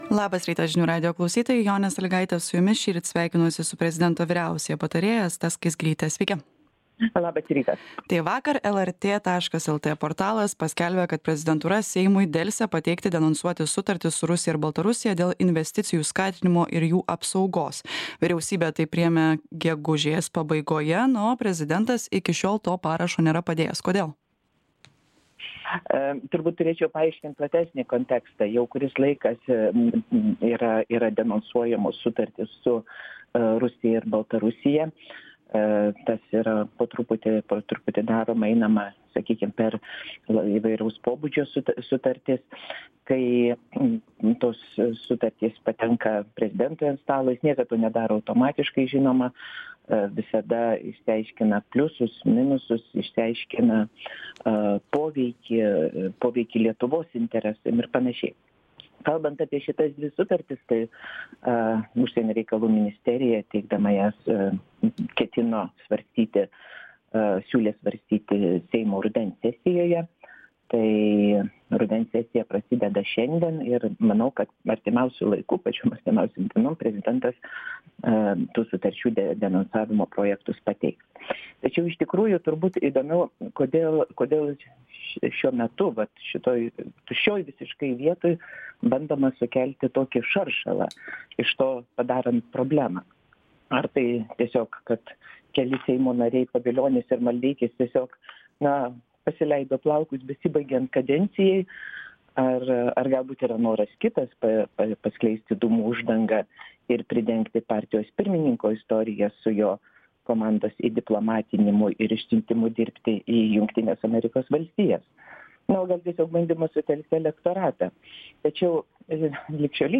Pokalbis su prezidento vyriausiąja patarėja Asta Skaisgiryte.